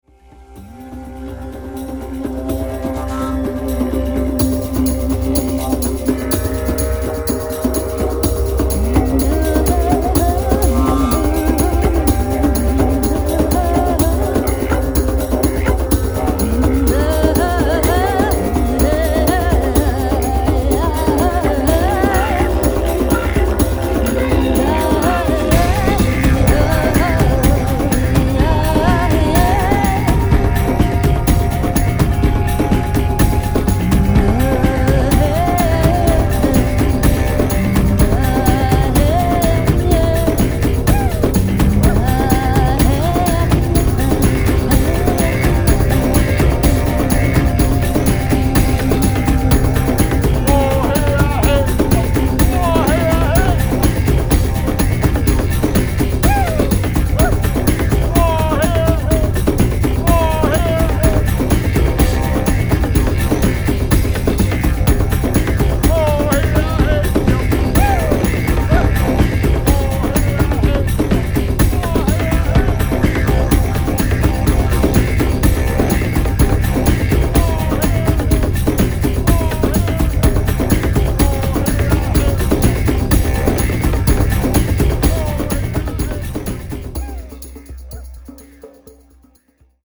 Meditationsmusik anbieten.